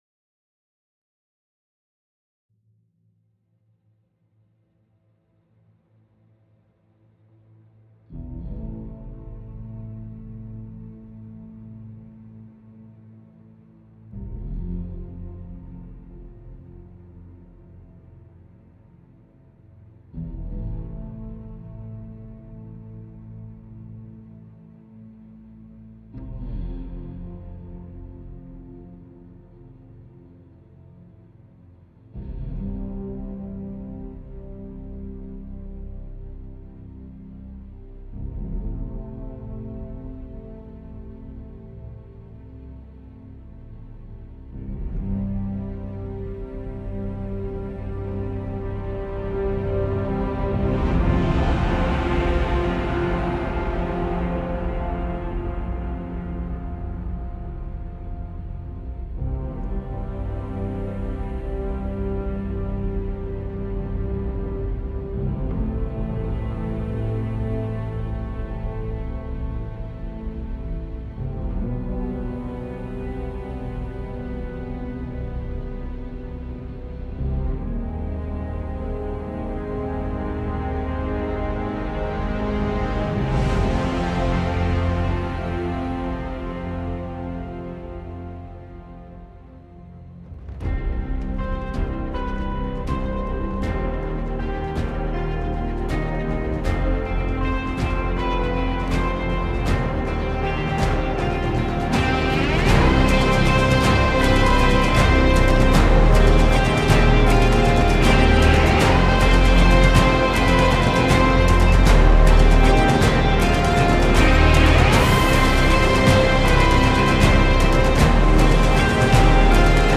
BEST ORIGINAL SCORE